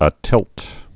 (ə-tĭlt)